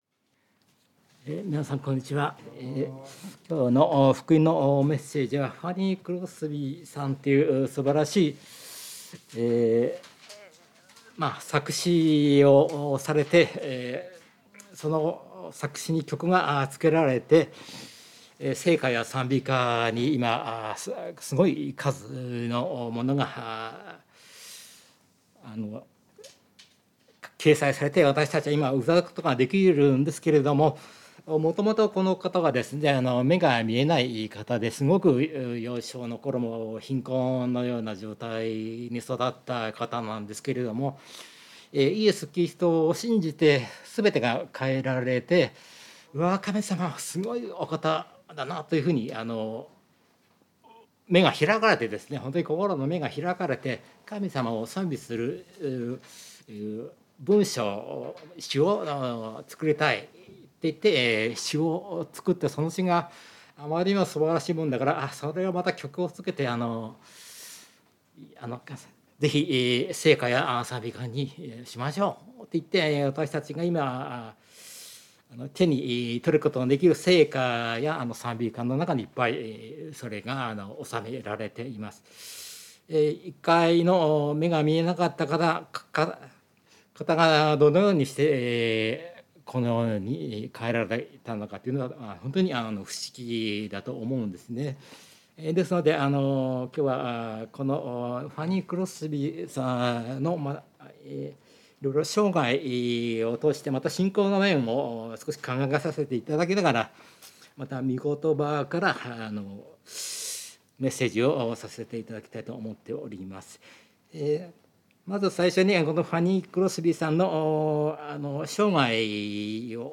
聖書メッセージ No.253